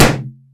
balloonpop.ogg